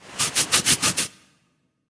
scratch.ogg.mp3